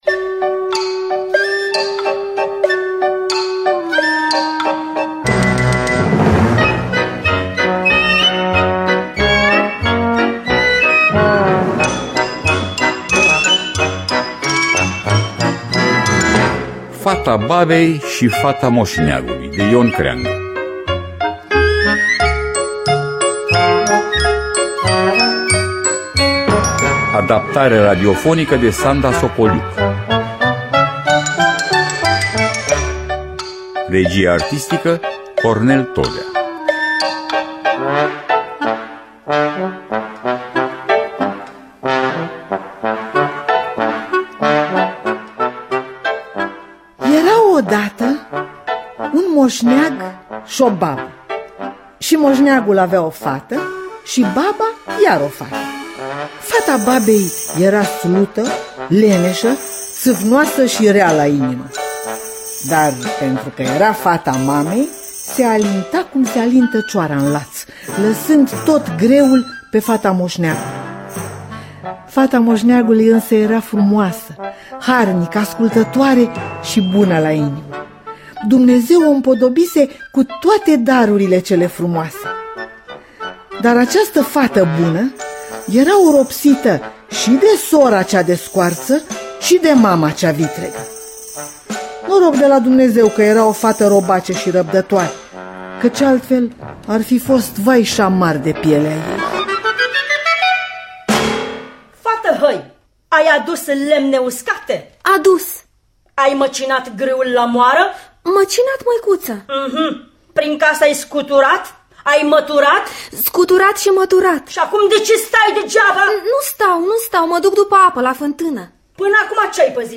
Fata babei și fata moșneagului de Ion Creangă – Teatru Radiofonic Online